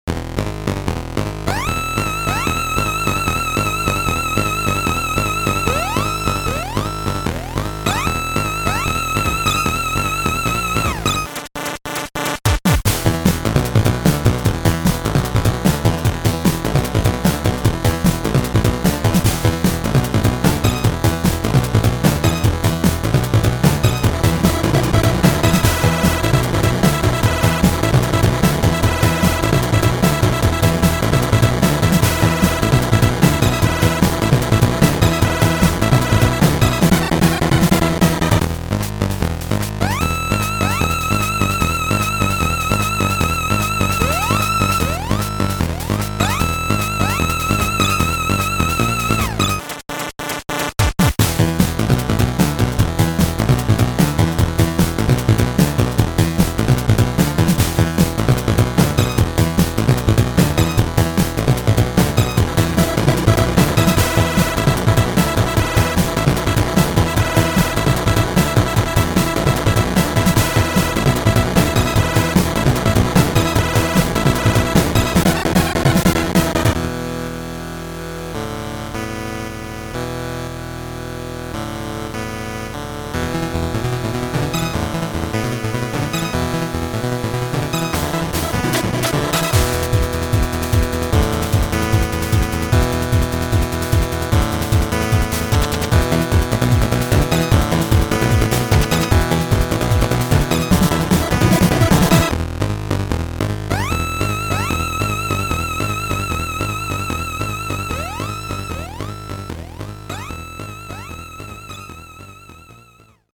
ppmck（ファミコン音源データコンパイラ）で作ったMP3/nsfファイルです。
バランスは VirtualNSF プレイヤー用に調整しています。
VRC6音源使用